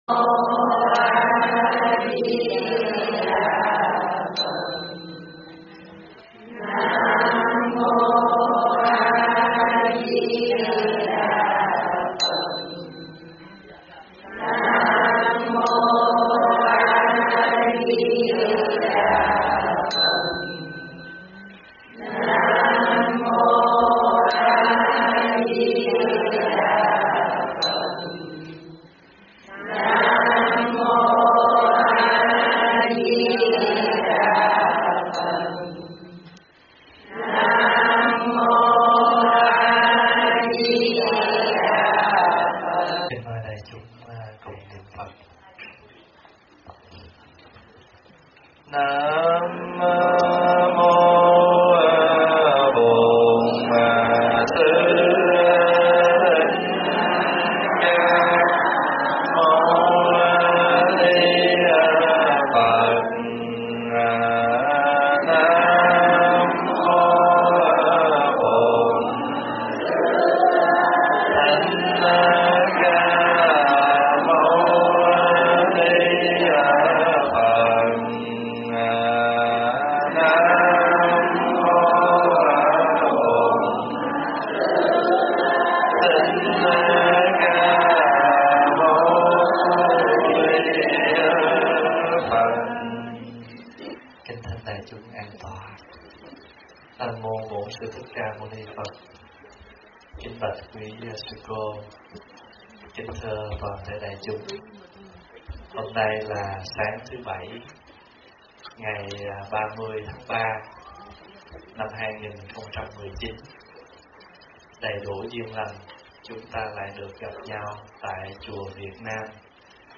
thuyết pháp
giảng tại chùa Việt Nam Seattle